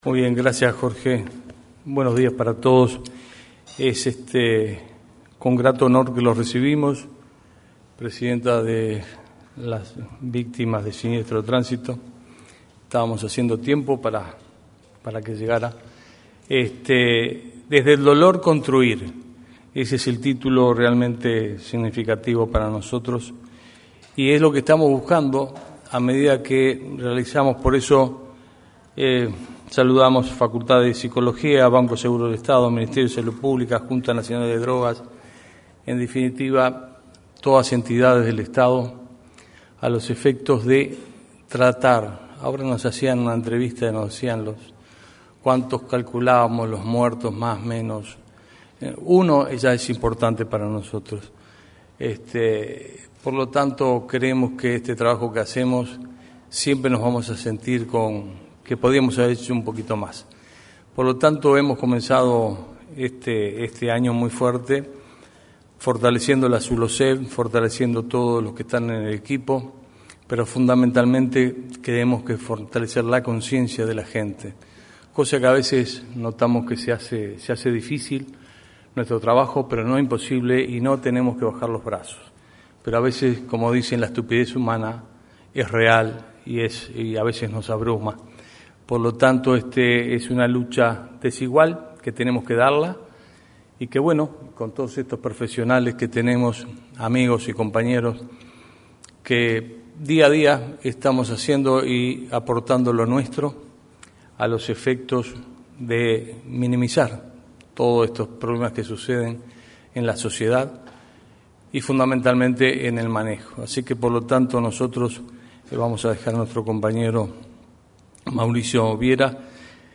En la oportunidad, se expresó el director de la Unidad Nacional de Seguridad Vial (Unasev), Carlos Manzor.
Declaraciones a la prensa de director de Unasev, Carlos Manzor